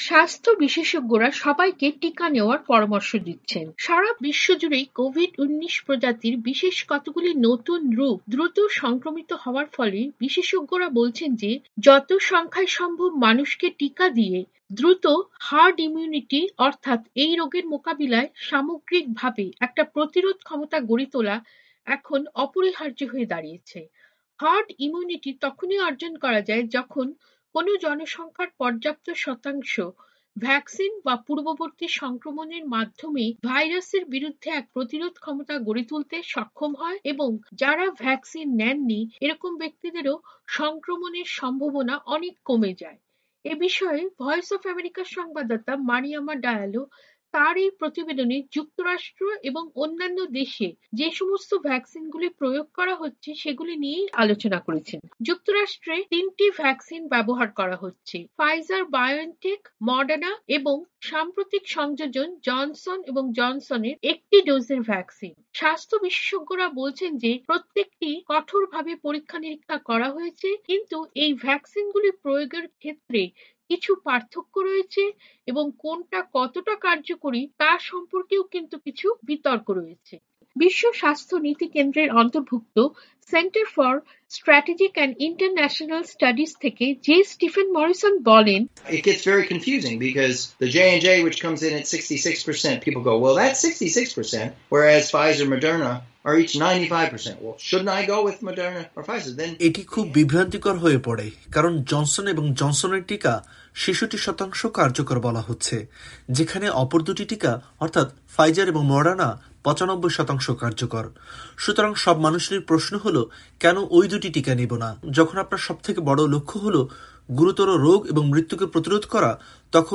প্রতিবেদনটি পড়ে শোনাচ্ছেন